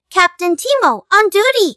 speech-style-transfer text-to-speech voice-cloning
F5-TTS, the new state-of-the-art in open source voice cloning
[+] Removed silence